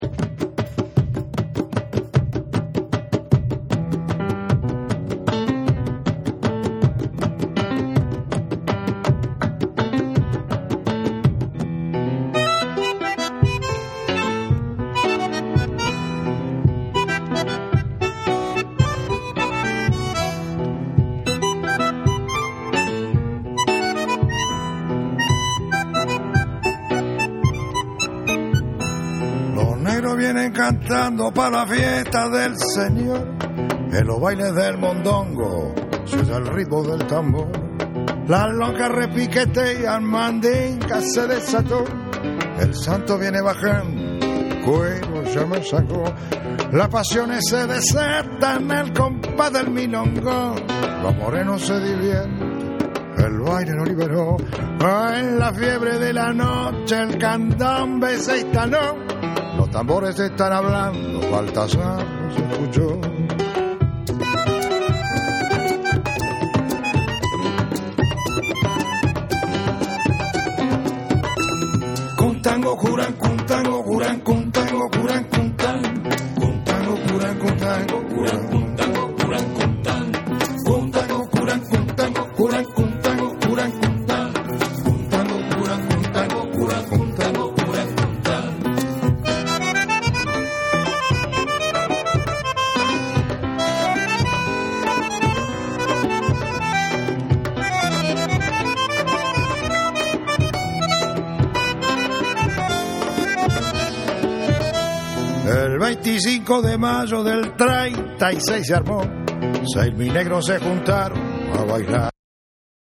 土着的なリズムに洗練されたメランゴリックな演奏が哀愁を誘う好作。
WORLD / CD